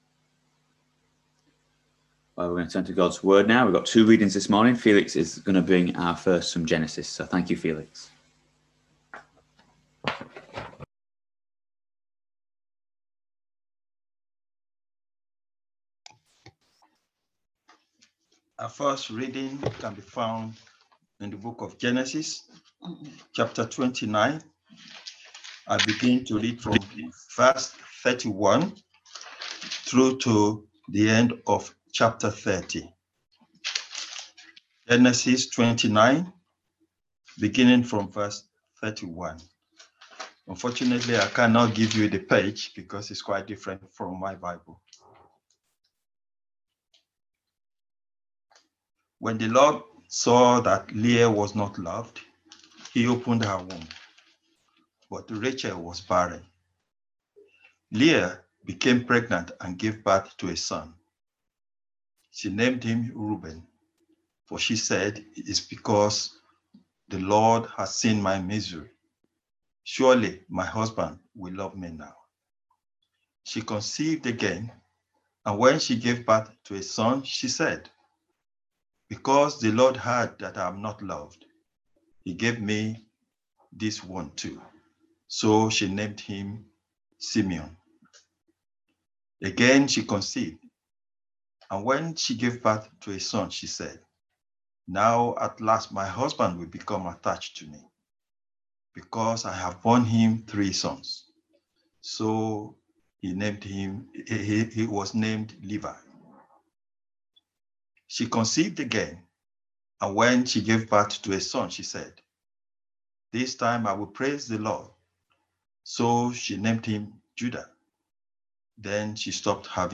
Genesis 29v31-30v24 Service Type: Sunday Morning Service Topics